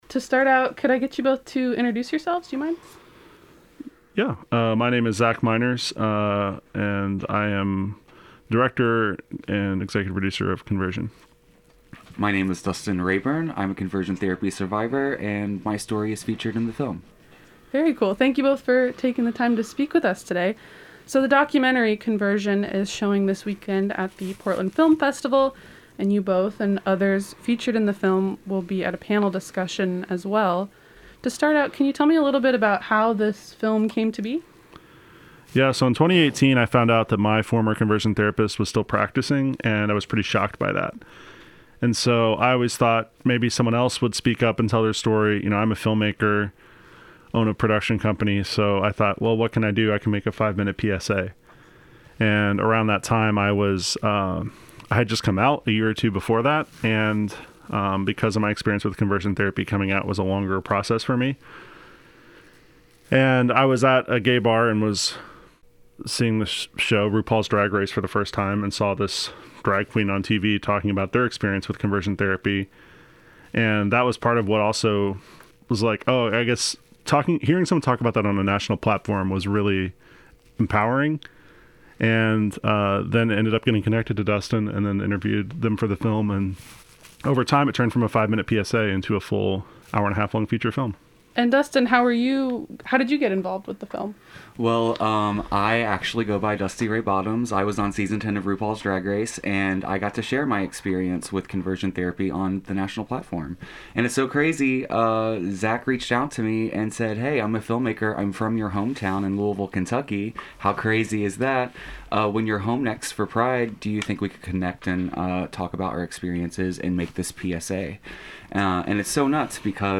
1014_conversion_interview_edited_long.mp3